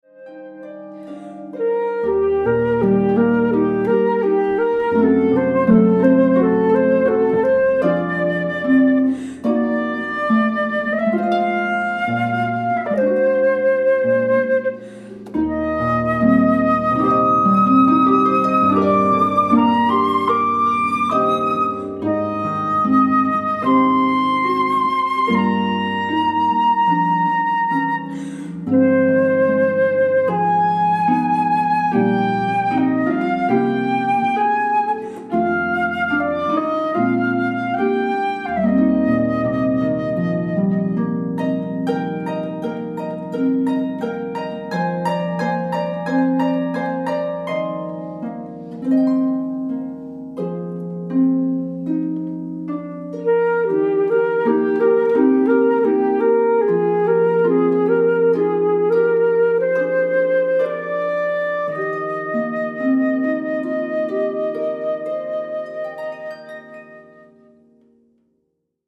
Jazz/Pop